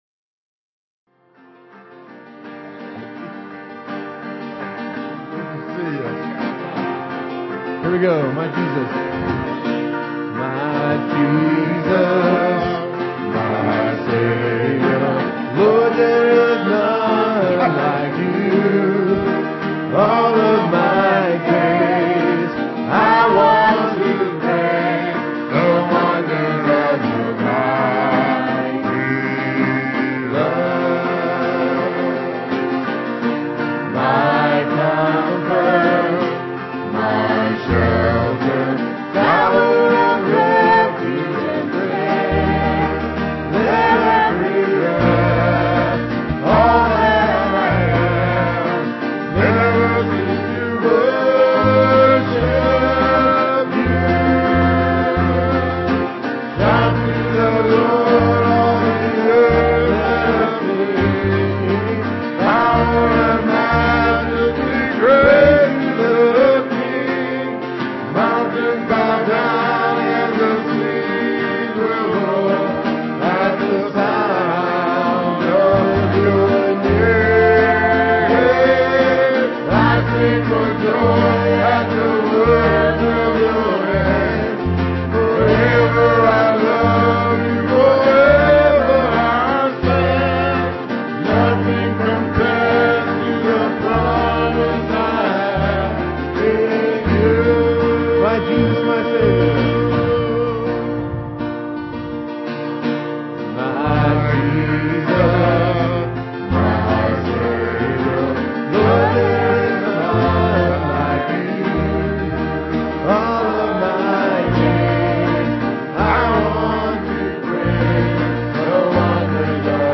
Guitars
keyboard
vocal solos
Piano and organ duet